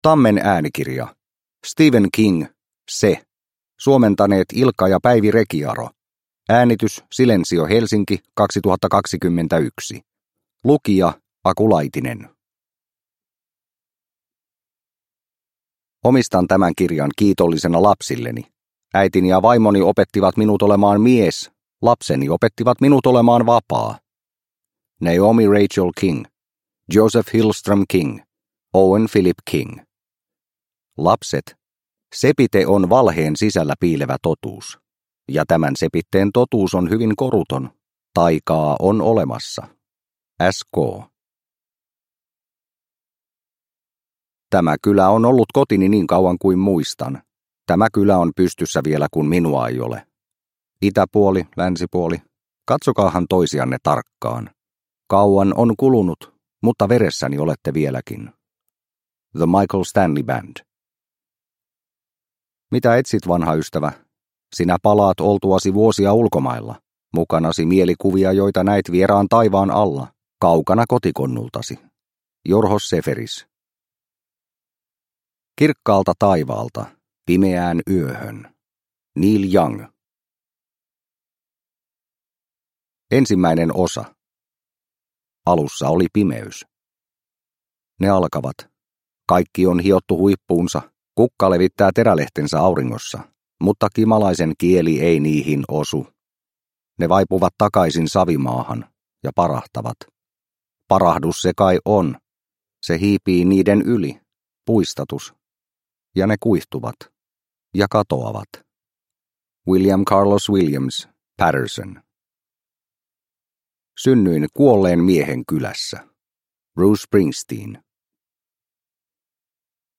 Se – Ljudbok – Laddas ner